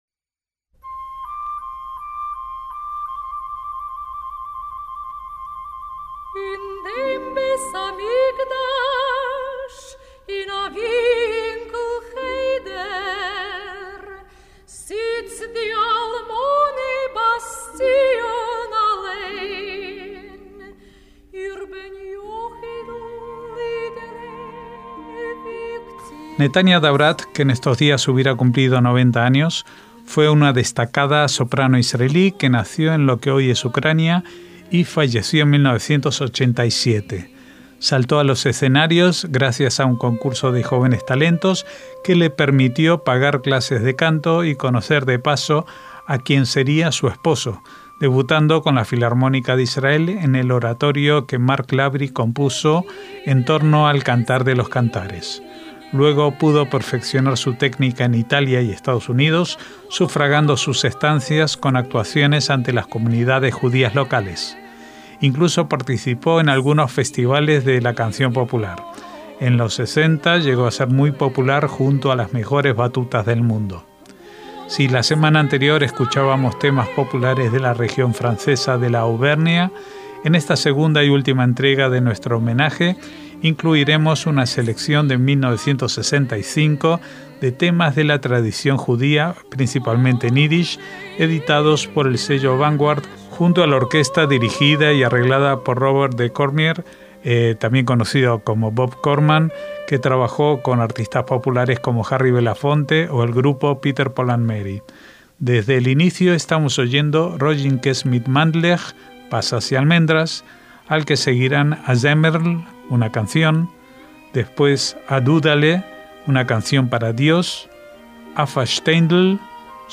Netania Davrath (y II): canciones tradicionales judías
MÚSICA CLÁSICA - Netania Davrath, que en estos días hubiera cumplido 90 años, fue una destacad soprano israelí que nació en lo que hoy es Ucrania y falleció en 1987.